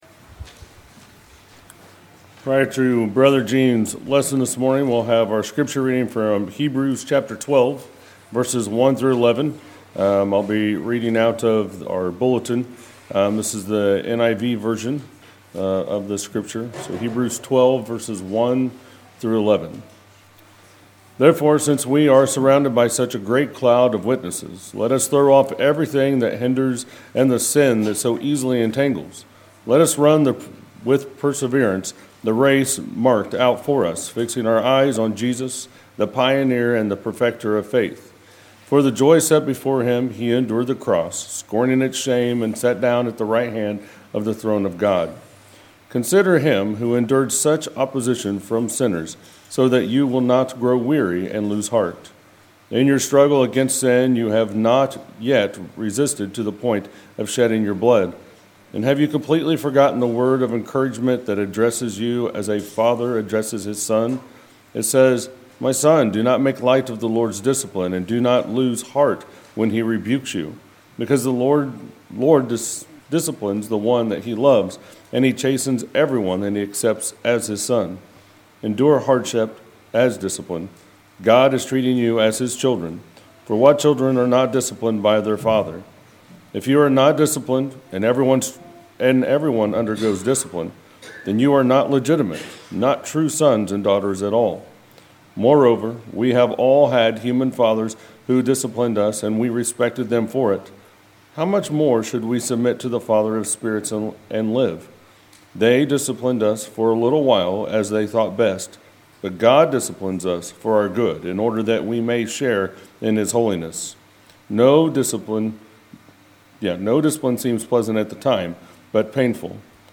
Sermons, March 3, 2019